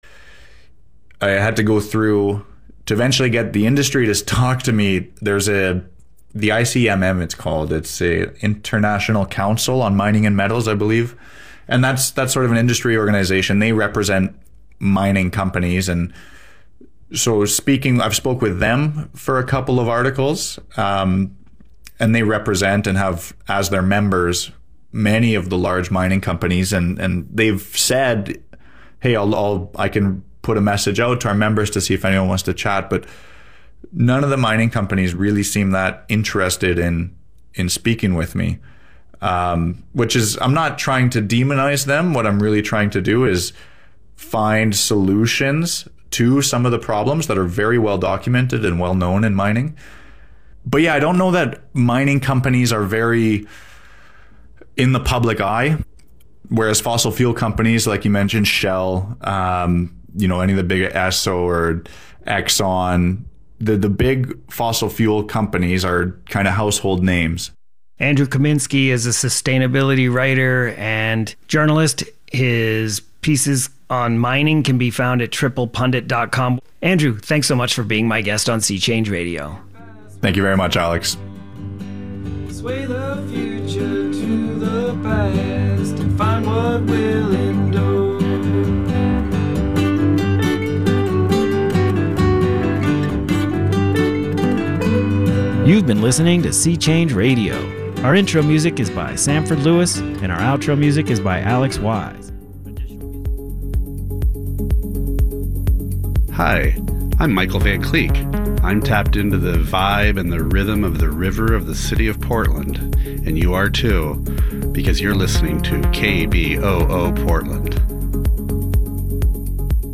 A panel broadcast reviewing the latest films and shows from the multiplex to the arthouse and beyond.